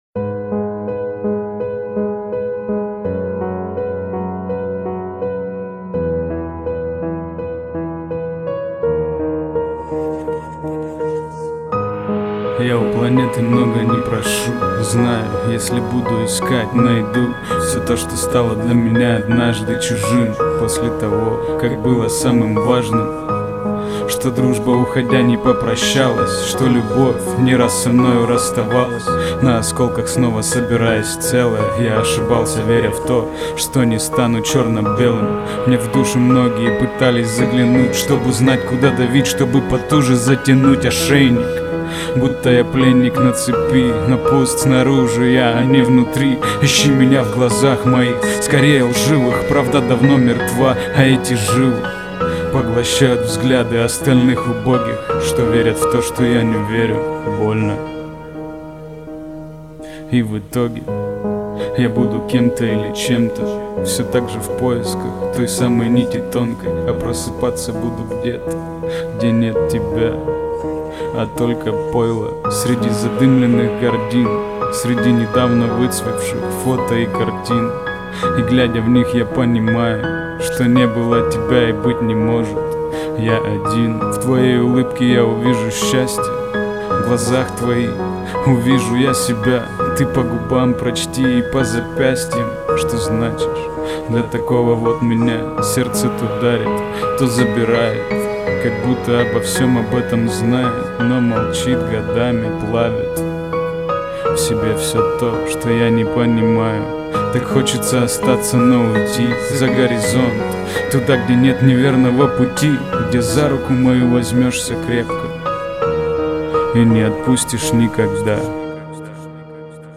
Приятный рэп… нежный хищник..